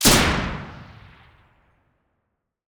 generalgun.wav